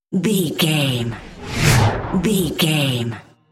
Whoosh fast trailer
Sound Effects
Fast paced
In-crescendo
Atonal
Fast
intense
whoosh